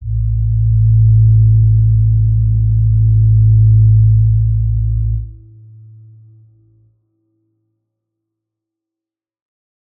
G_Crystal-A2-mf.wav